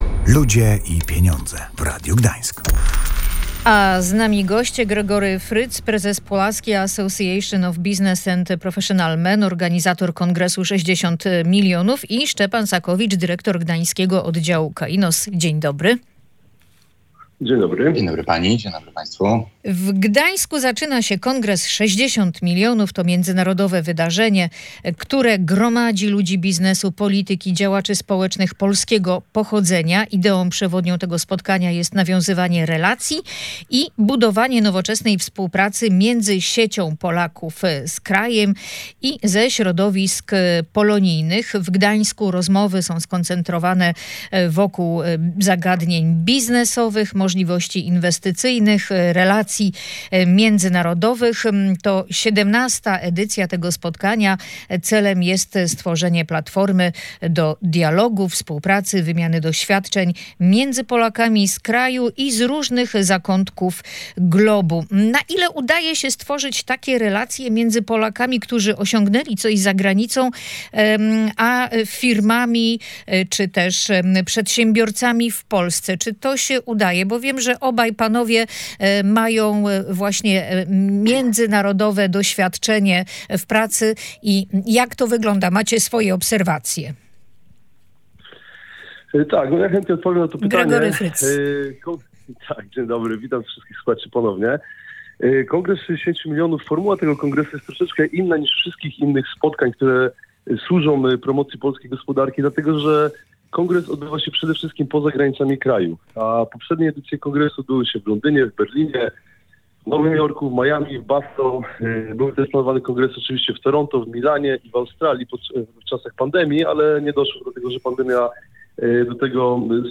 O tym w audycji „Ludzie i Pieniądze” rozmawiali goście